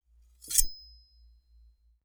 Metal_86.wav